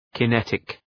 Shkrimi fonetik {kı’netık}